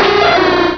Cri_0198_DP.ogg